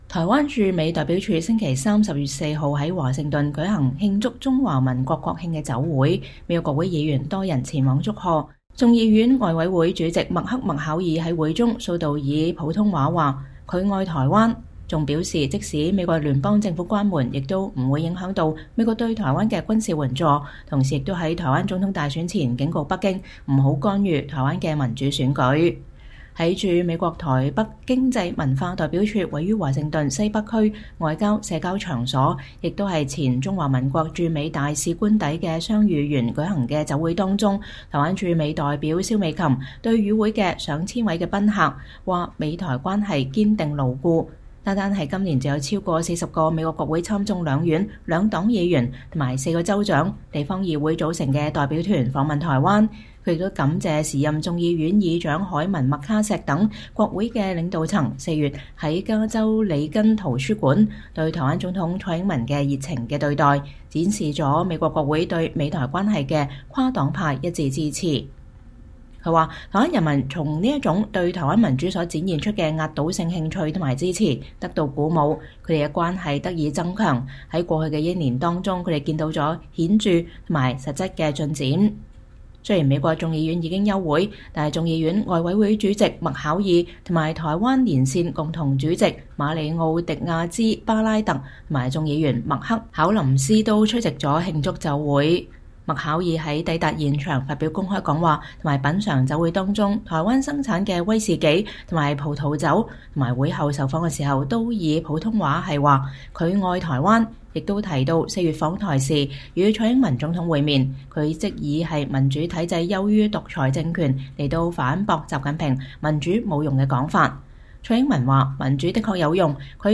台灣駐美代表處星期三(10月4日)在華盛頓舉行「慶祝中華民國112年國慶」酒會，美國國會議員多人前往祝賀，眾院外委會主席麥克·麥考爾(Mike McCaul)在會中數度以普通話高喊：「我愛台灣」，還表示即便美國聯邦政府關門也不會影響到美國對台灣的軍事援助，同時也在台灣總統大選前警告北京，不要干預台灣的民主選舉。